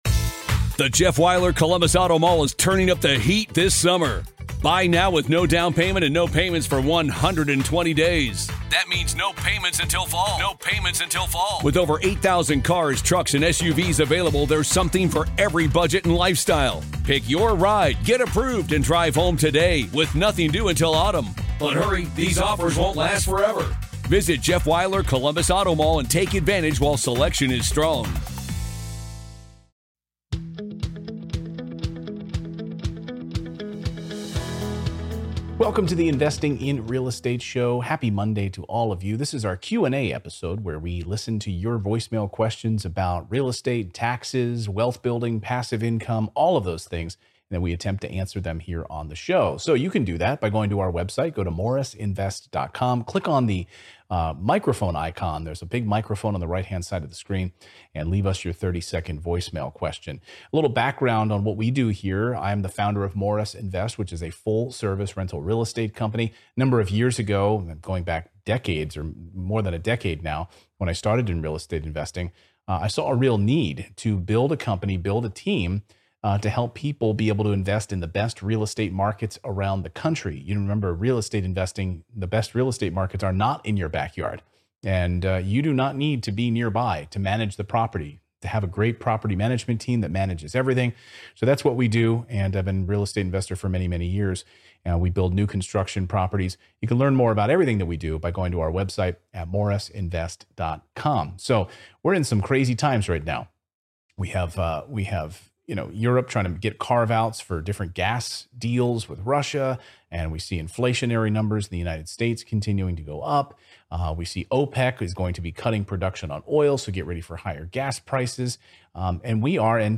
There's no one-size-fits all answer to this question, but on this Q&A episode I'm sharing my thoughts on buying a home!